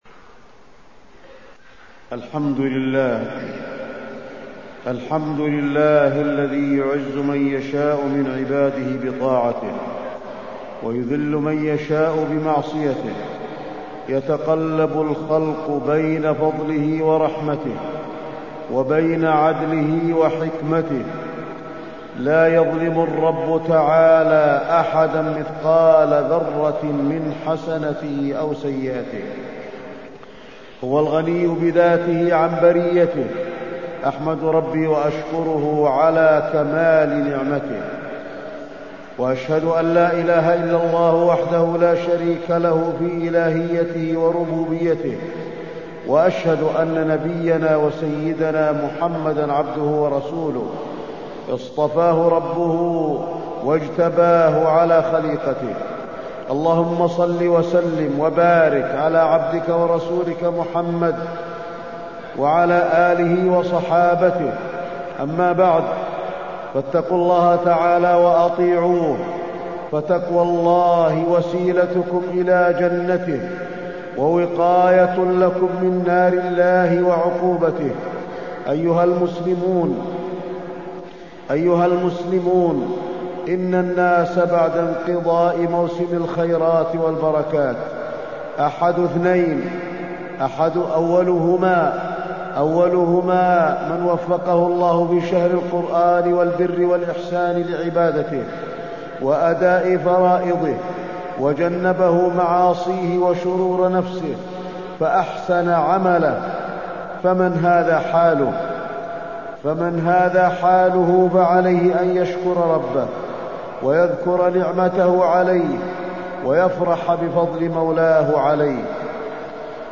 تاريخ النشر ٦ شوال ١٤٢٥ هـ المكان: المسجد النبوي الشيخ: فضيلة الشيخ د. علي بن عبدالرحمن الحذيفي فضيلة الشيخ د. علي بن عبدالرحمن الحذيفي الاستعانة بالله